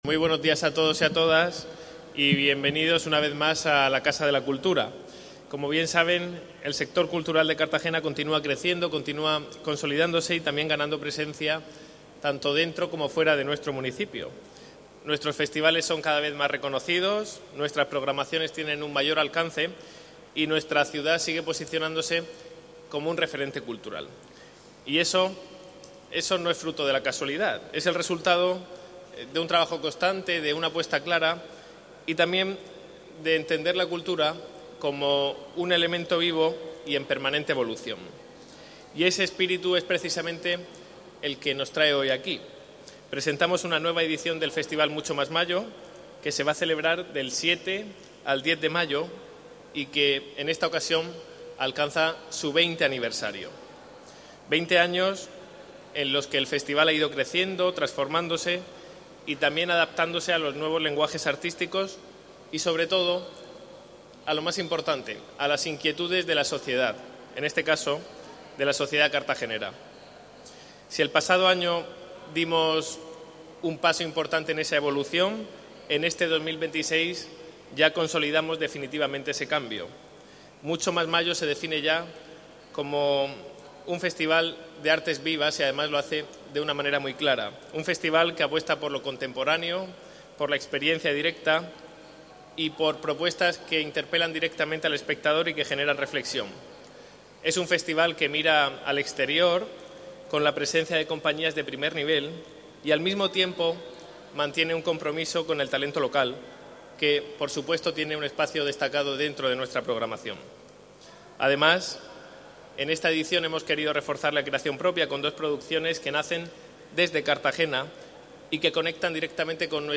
Declaraciones del concejal Ignacio J�udenes